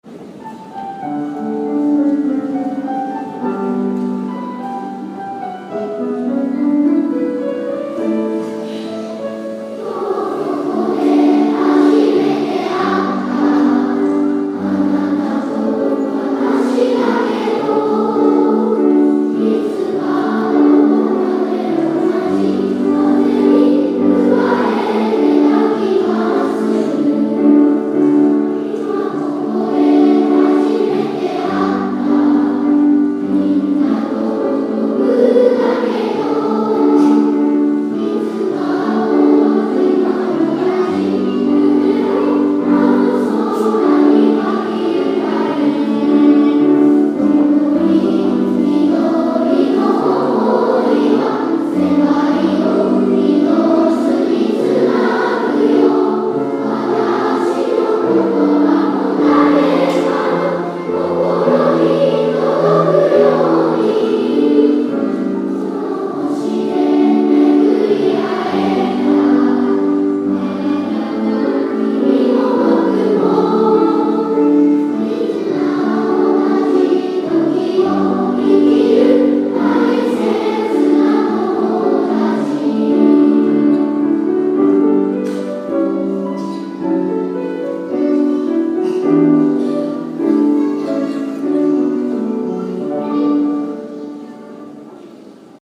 2016年6月4日（土）ふれあい科　１１ｔｈ大空創立記念コンサート
この地球の友だちだから」全校合唱です。
客席にいる卒業生からも歌声が聞こえるぐらい、大空小学校で大事にしている曲で、これからも歌い続けていきます！